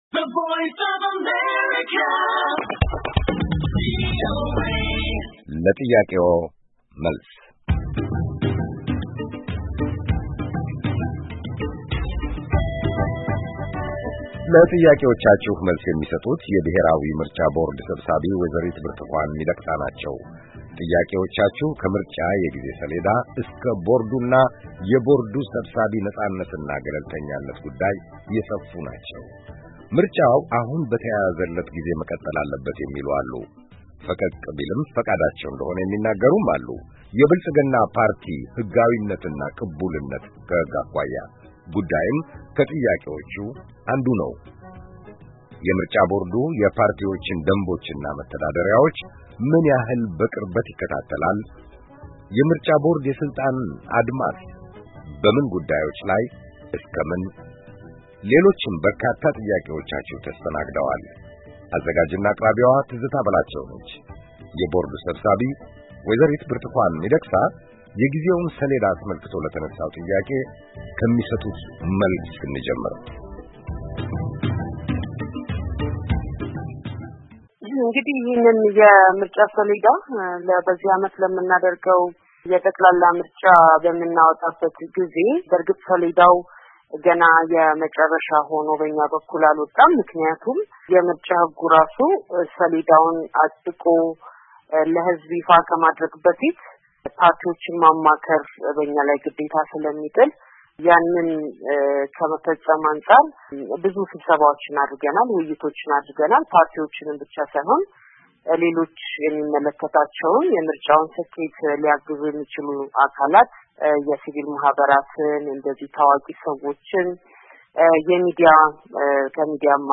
የምርጫ ቦርድ ሰብሳቢ ወ/ት ብርቱካን ለአድማጮች የሰጡት መልስ
ለጥያቄዎቻችሁ መልስ የሚሰጡት የብሄራዊ ምርጫ ቦርድ ሰብሳቢ ወ/ት ብርቱካን ሚዲቅሳ ናቸው። ጥያቄዎቻችሁ ከምርጫ የጊዜ ሰሌዳ እስከቦርዱና የቦርዱ ስብሳቢ ነፃነትና ገለልተኛነት የሰፉ ናቸው።